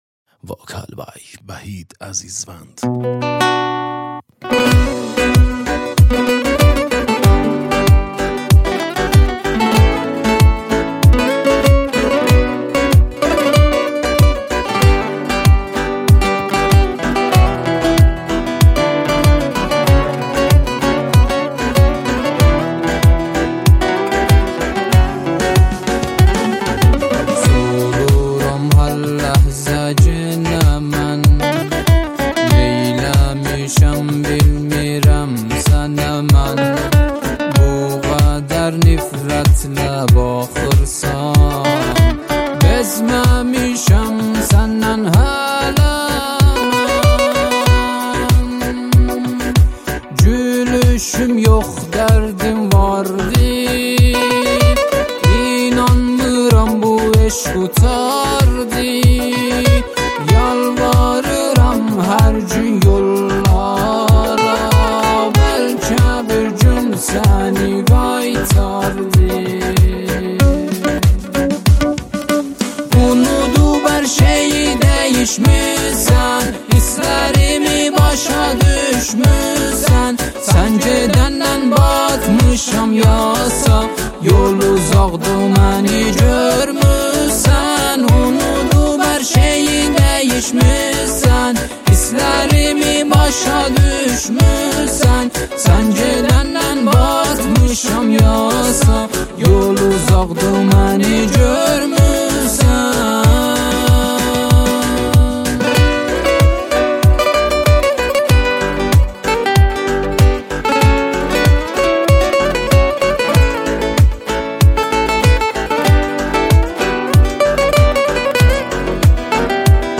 آهنگ آذری و ترکی